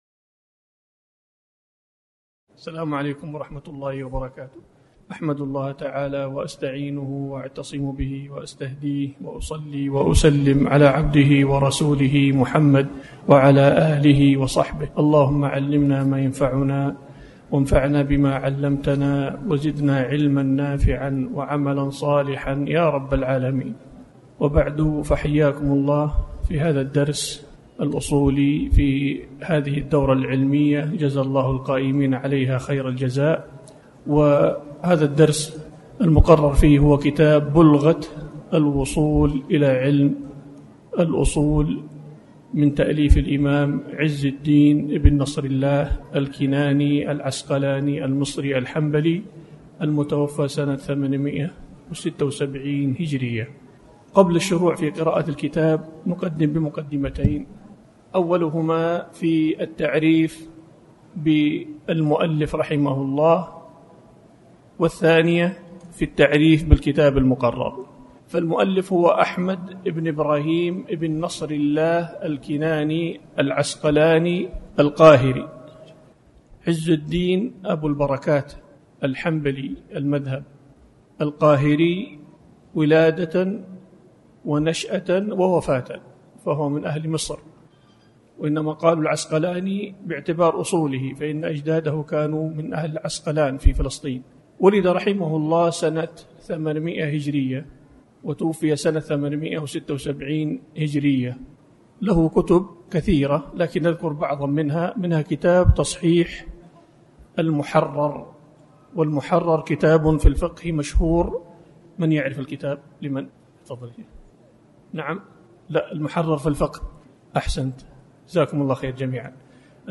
بلغة-الوصول-3ذو-القعدة-الدورة-العلمية.mp3